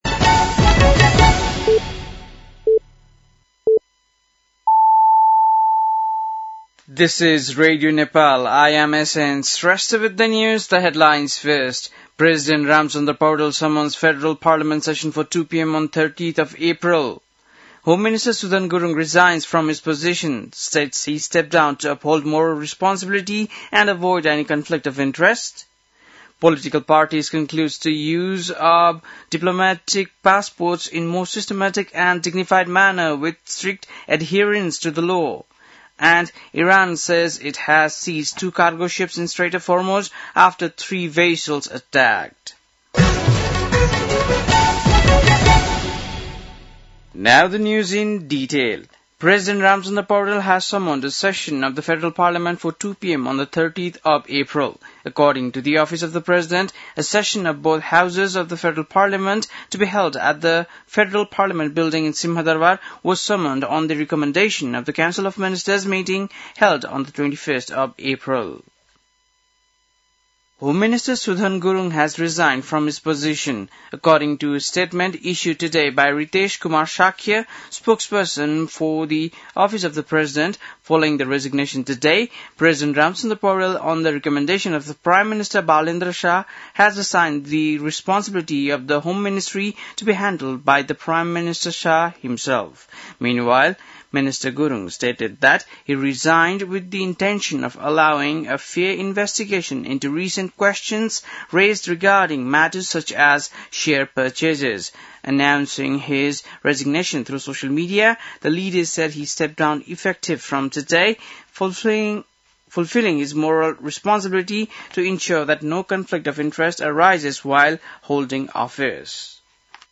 बेलुकी ८ बजेको अङ्ग्रेजी समाचार : ९ वैशाख , २०८३
8-pm-english-news-1-09.mp3